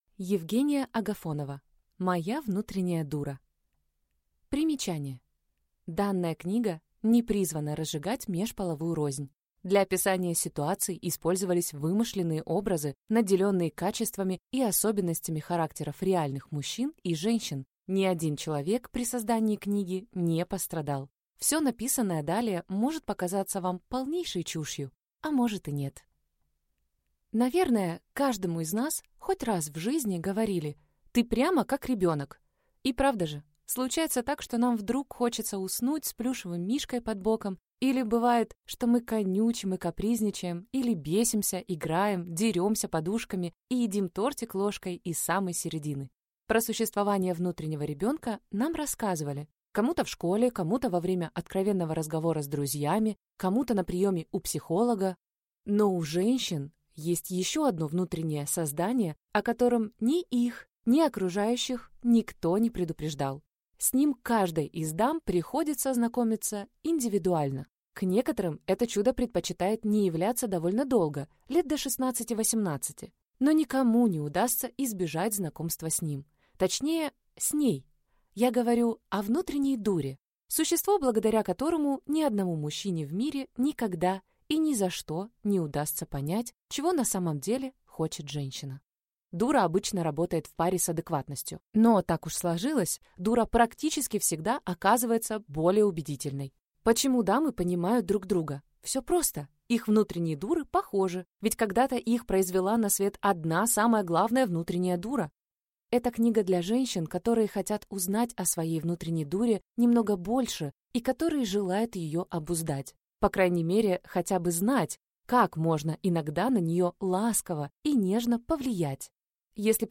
Аудиокнига Моя внутренняя дура | Библиотека аудиокниг